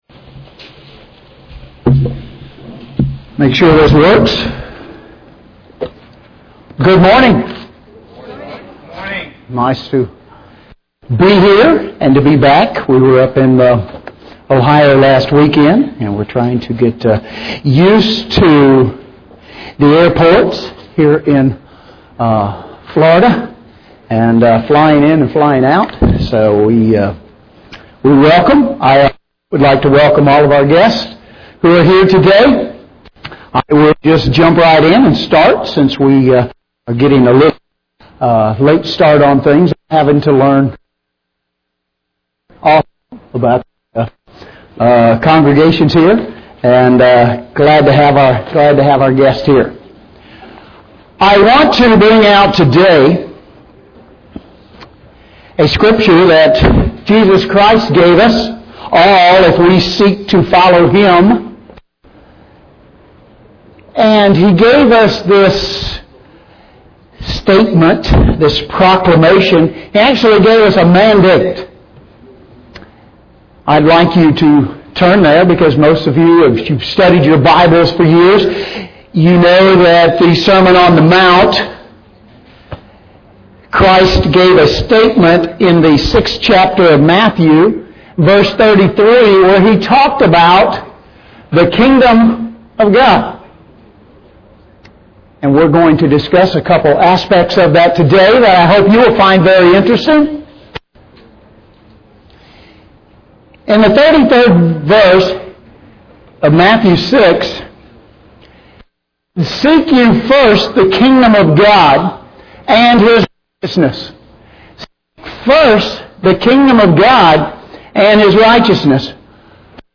This Kingdom of God seminar challenges listeners to consider a couple of related aspects of the Sermon on the Mount.